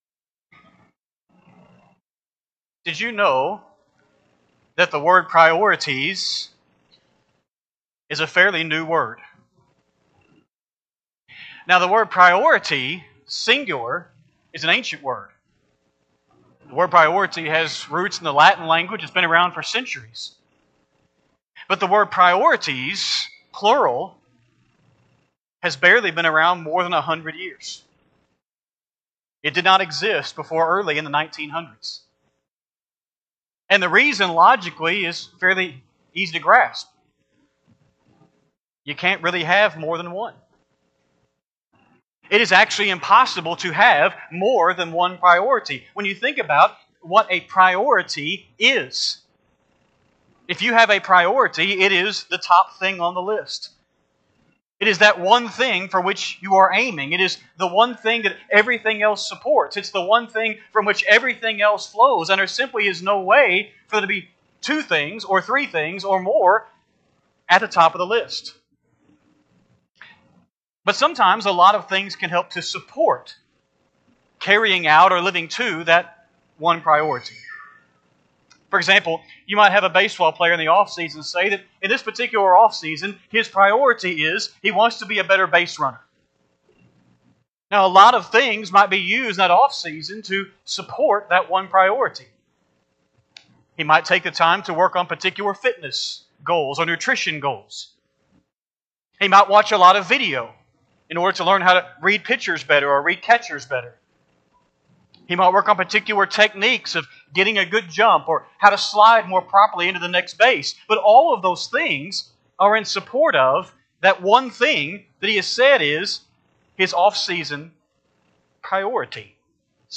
5-18-25-Sunday-AM-Sermon.mp3